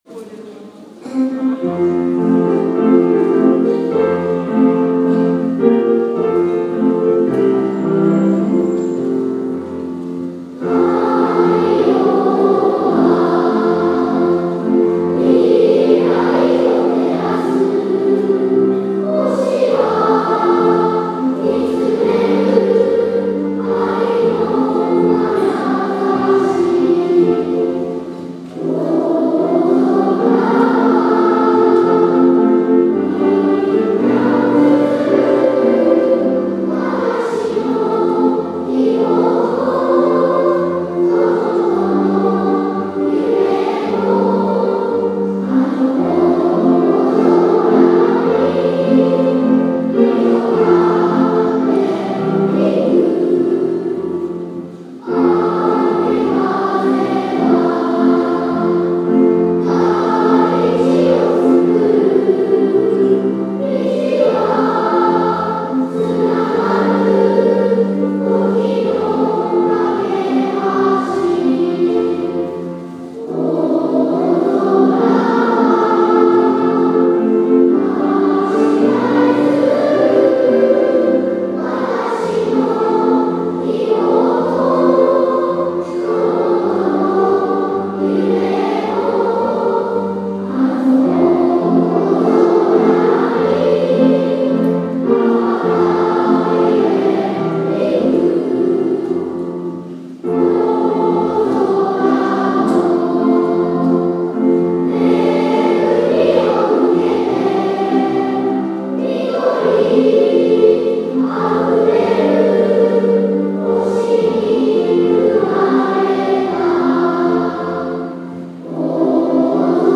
2016年6月4日（土）ふれあい科　１１ｔｈ大空創立記念コンサート
校歌」会場２部合唱です。
会場にいるみんなで「大空小学校校歌」を歌い、大空小学校のお誕生日をお祝いします♪